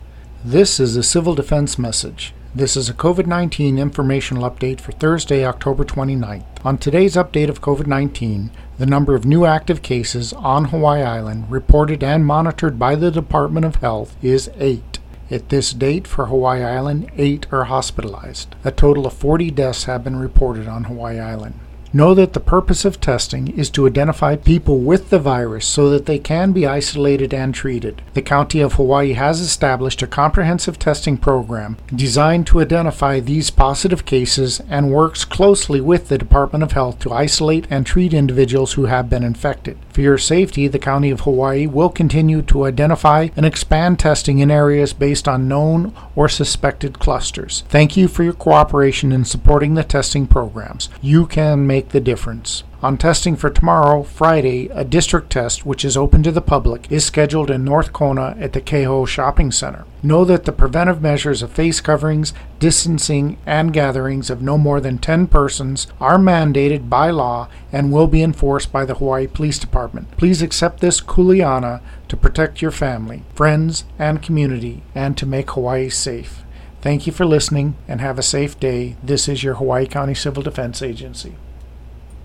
From the Hawaiʻi County Civil Defense radio message: